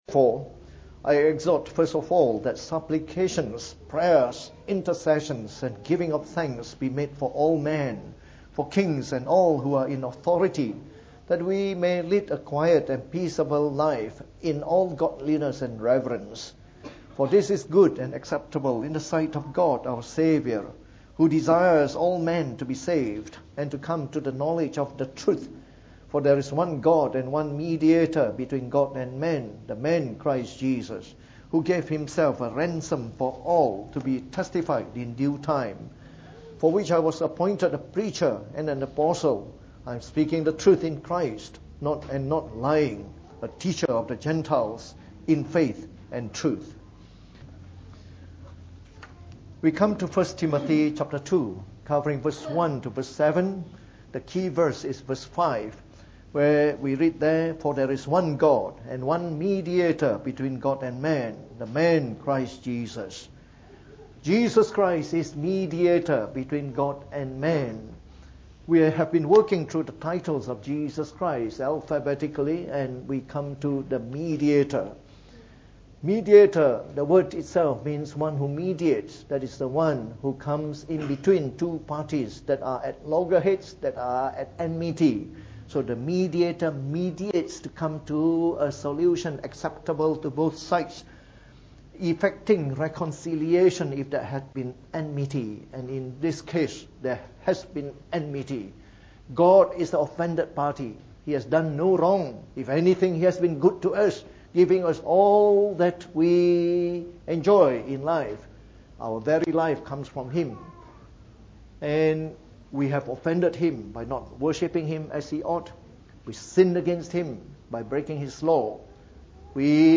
Preached on the 15th of April 2018. From our series on the Titles of Jesus Christ delivered in the Evening Service.